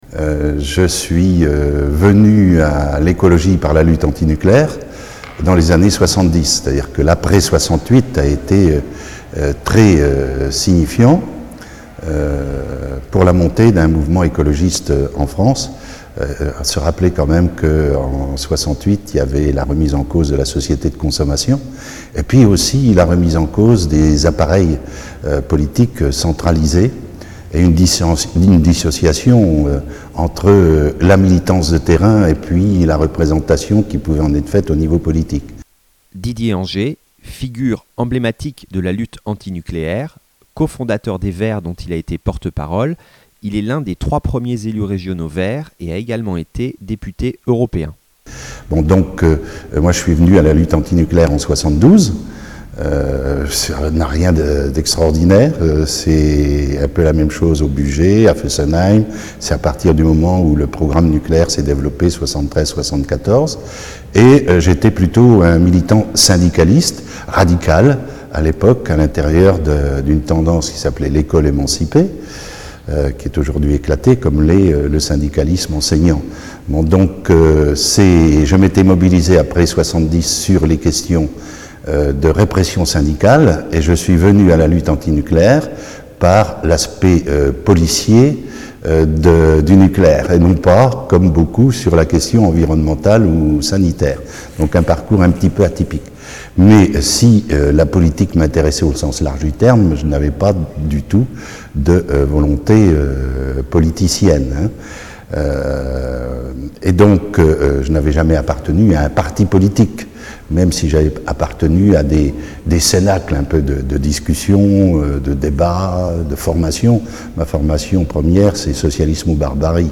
Témoignage de Didier Anger (2009)